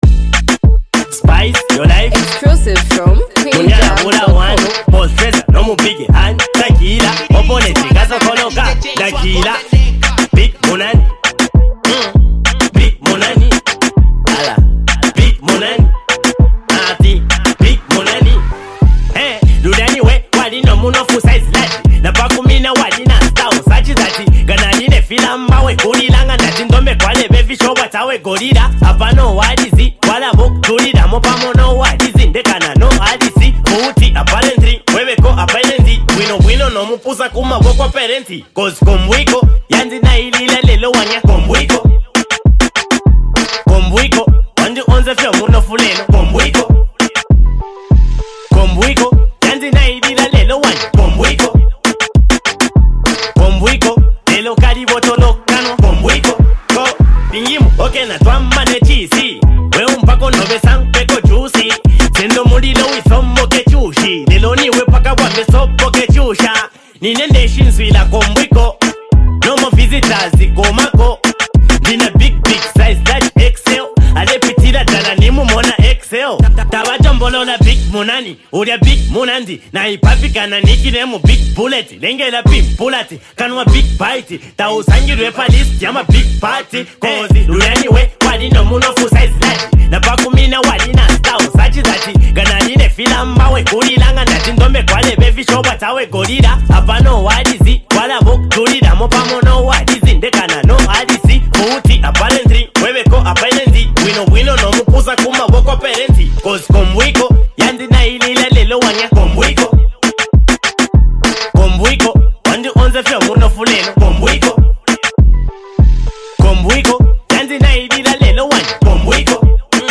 Highly multi talented act and super creative rapper